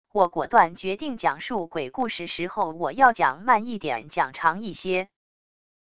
我果断决定讲述鬼故事时候我要讲慢一点讲长一些wav音频生成系统WAV Audio Player